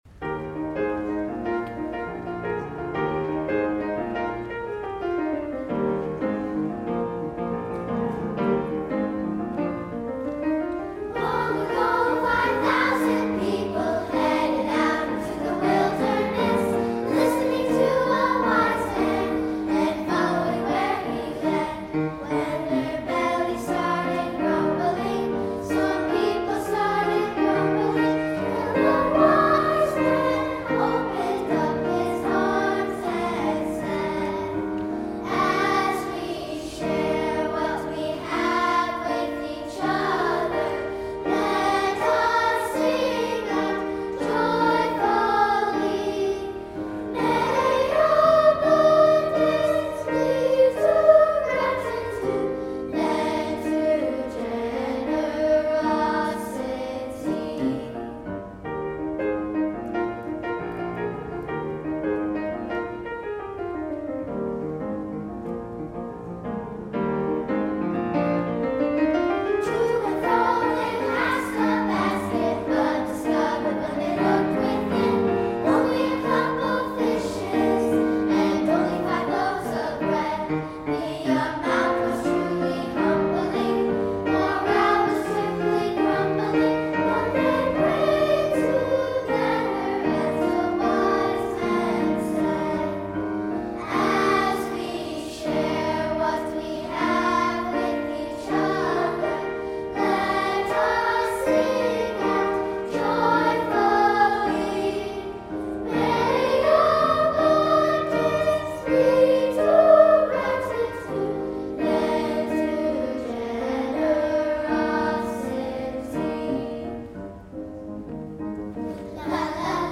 SA, piano